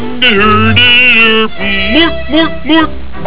dont understand what he says though